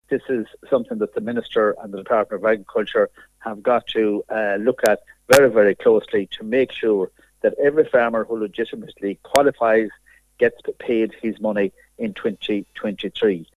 Deputy Canney says the system as it currently stands is going to leave a lot of applicant farmers getting nothing from the scheme and is grossly unfair: